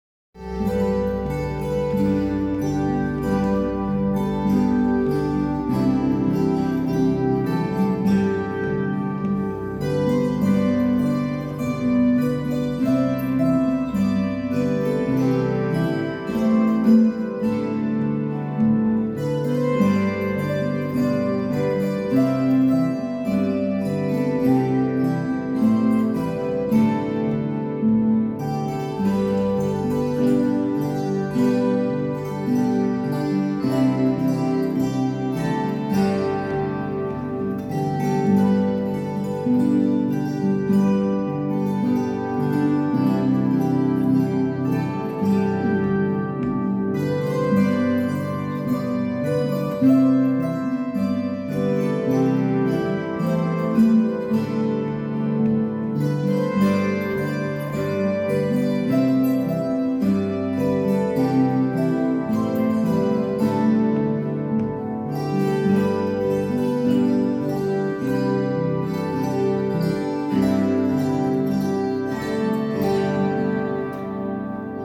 Veeh-Harfen Klänge aus dem Hospiz Velbert